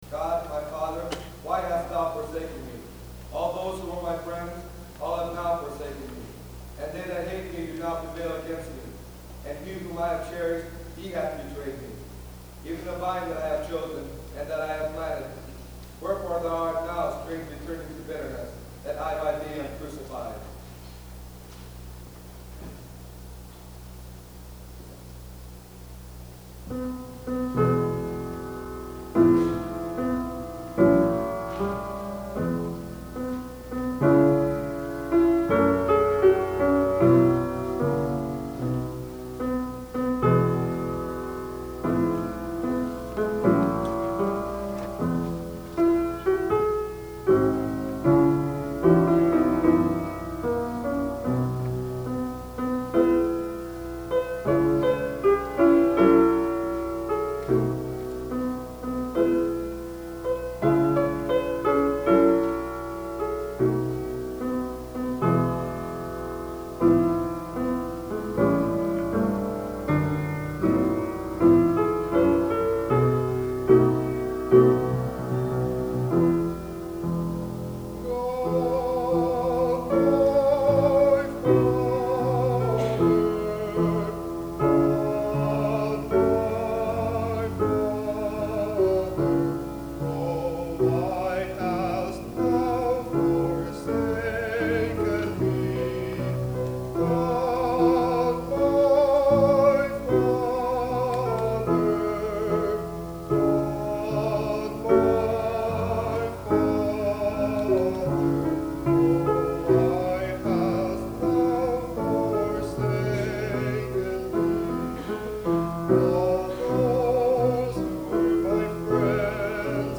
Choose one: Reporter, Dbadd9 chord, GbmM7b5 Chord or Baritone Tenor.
Baritone Tenor